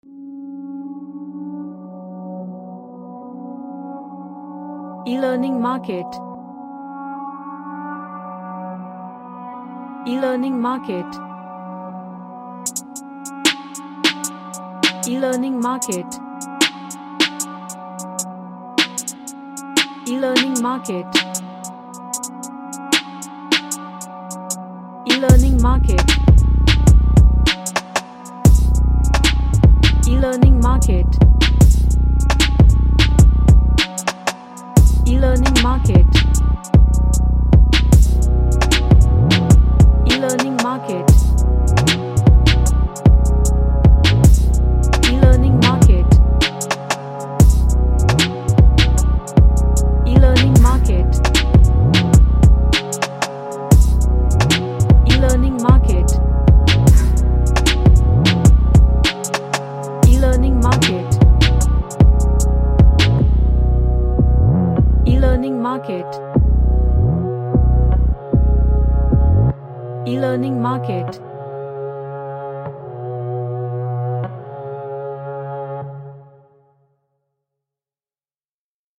A dark and tight sounding Drill Beat
Dark / Somber